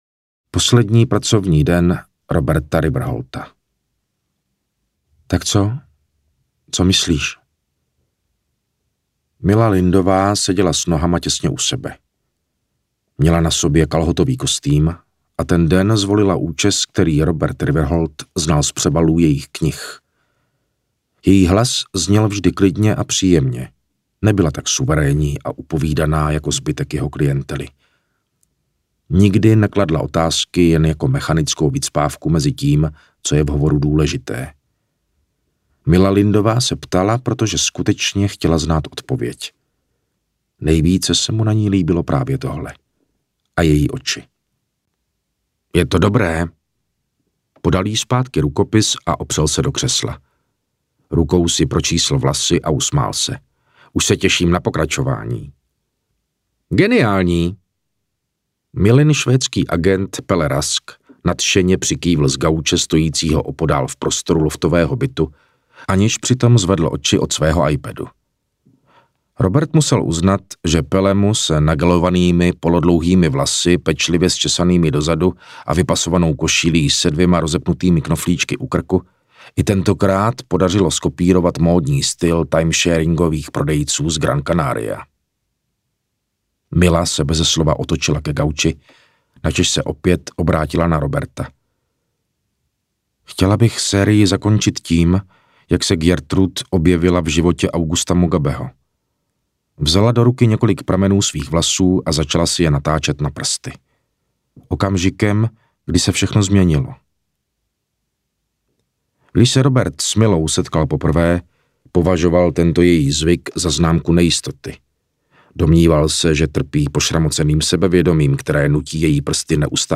Setkáme se v ráji audiokniha
Ukázka z knihy
• InterpretMartin Finger